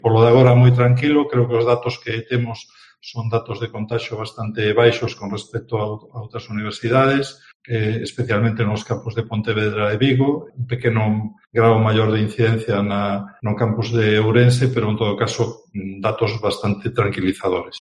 Declaraciones del rector de la Universidad de Vigo, Manuel Reigosa, sobre la incidencia de la COVID-19